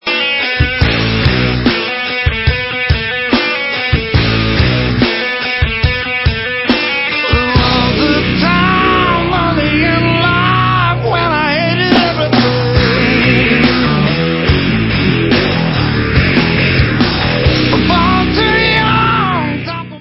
• styl: Alternative Rock, Hard Rock